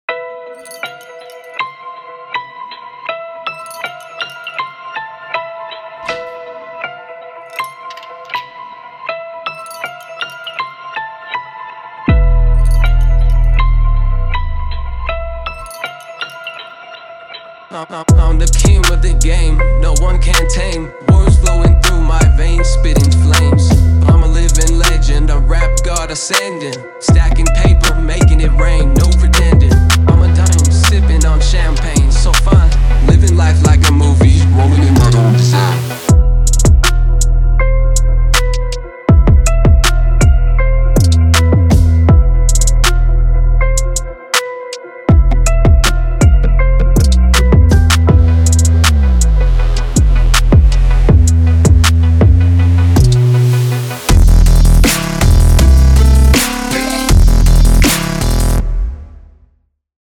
TRAP HIP-HOP
Dark / Ominous / Gritty /Rap